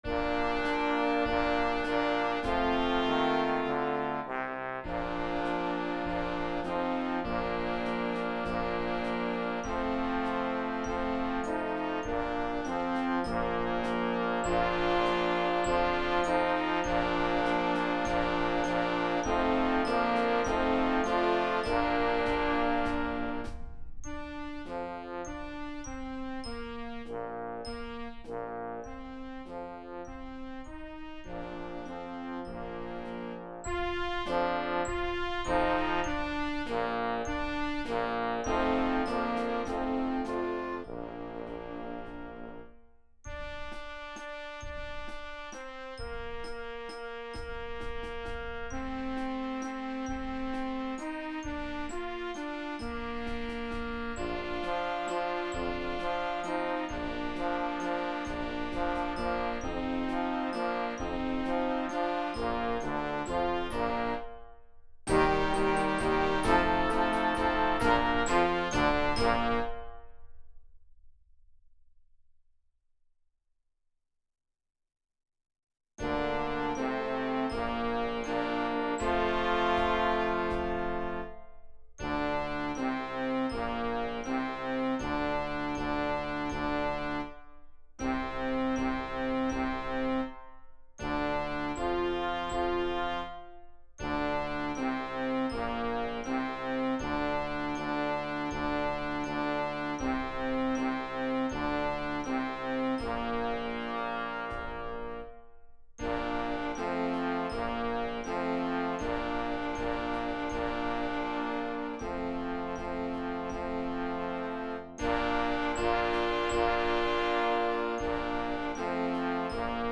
This is a suite of three children's songs arranged for beginning band.